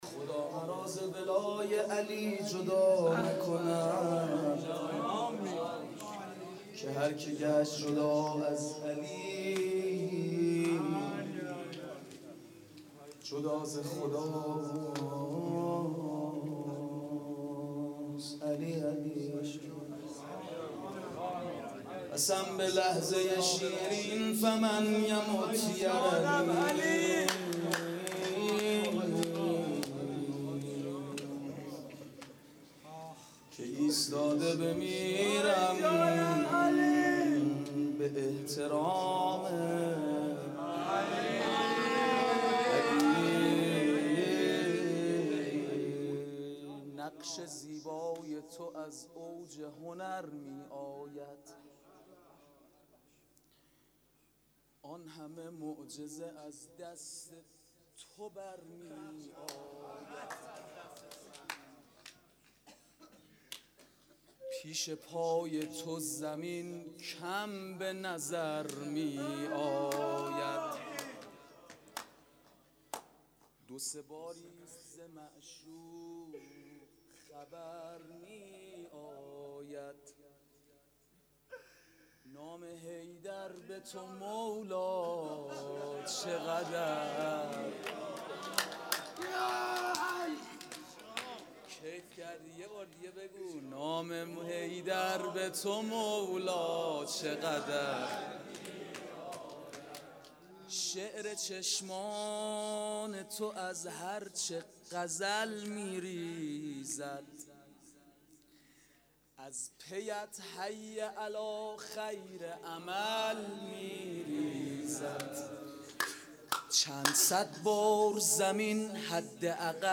مدح
خدا مرا ز ولای علی|شب پنجم فاطمیه ۹۴